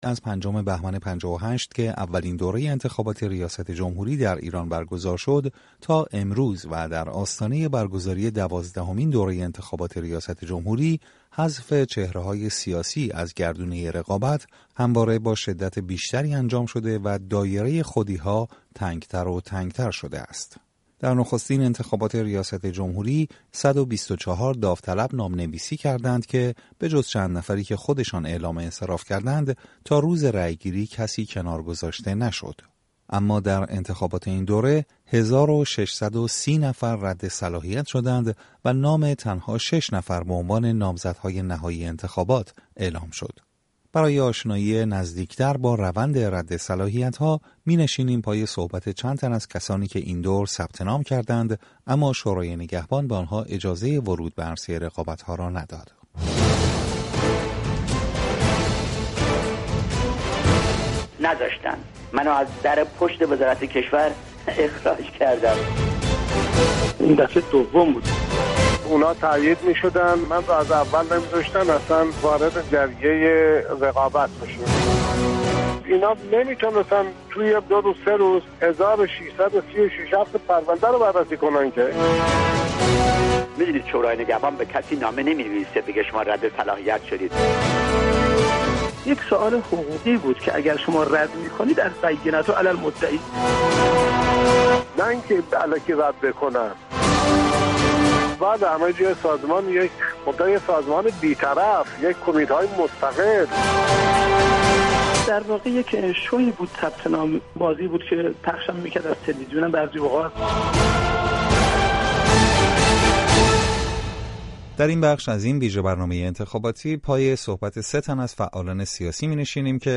میزگرد رادیو فردا